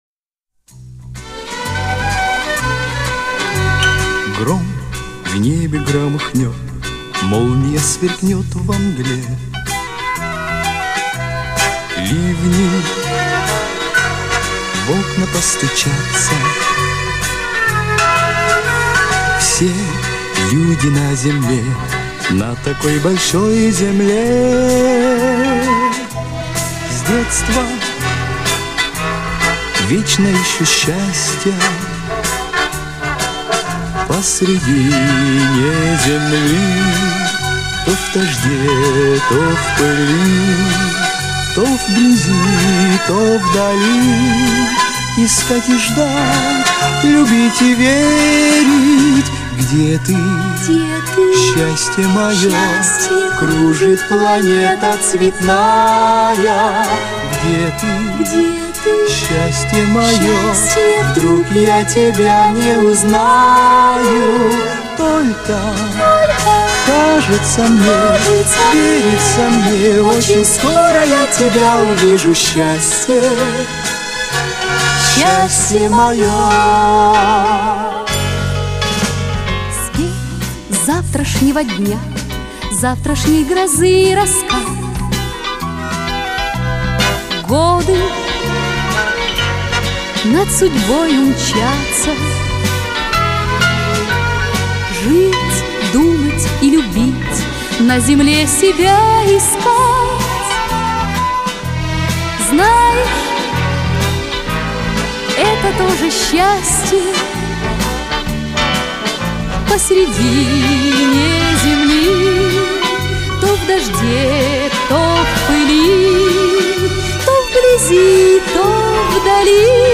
Это медленное исполнение, так поется, так и должно быть.